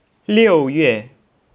(Click on any Chinese character to hear it pronounced.
liuyue.wav